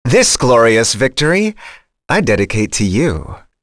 Fluss-Vox_Victory_b.wav